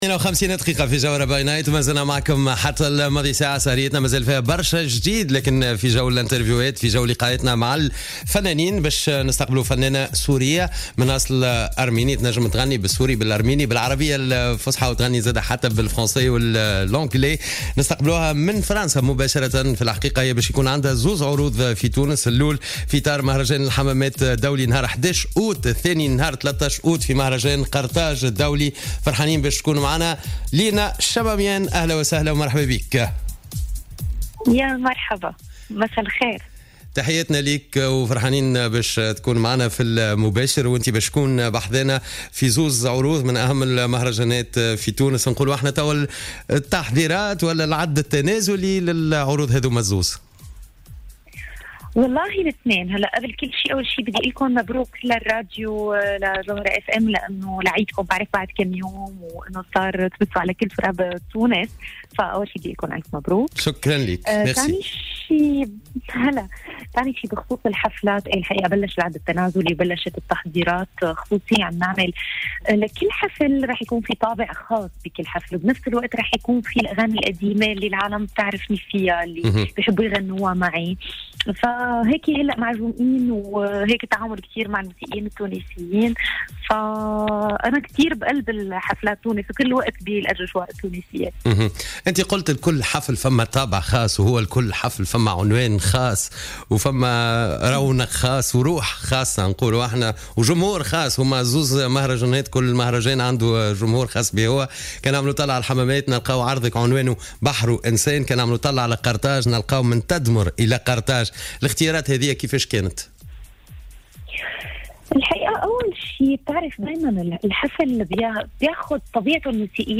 قالت الفنانة السورية لينا شماميان في مداخلة لها في برنامج Jawhara By Night" مساء اليوم الأحد على الجوهرة "اف ام" إن التحضيرات لحفليها بقرطاج يوم 13 أوت والحمامات يوم 11 أوت 2017 انطلقت منذ فترة.